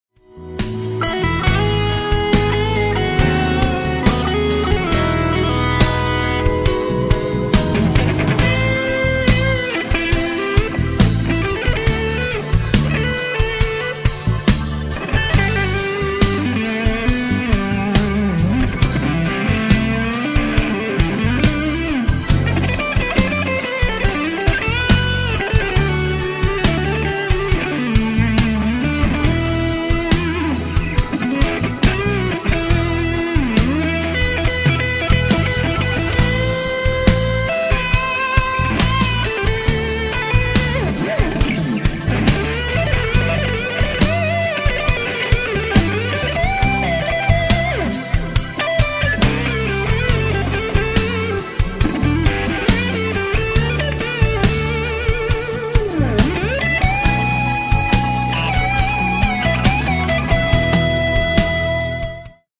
balada, latin outro